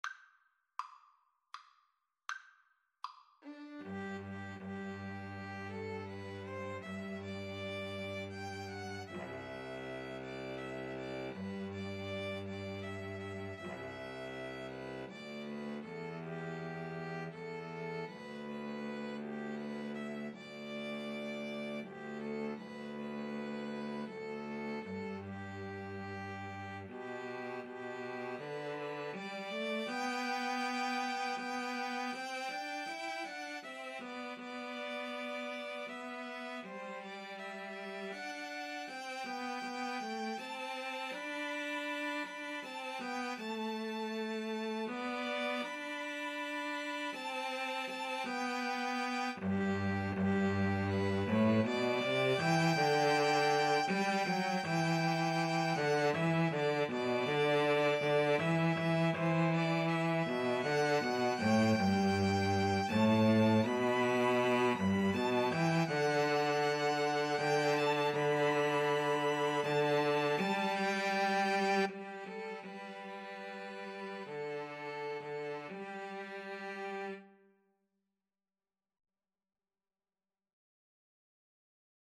G major (Sounding Pitch) (View more G major Music for 2-Violins-Cello )
Andante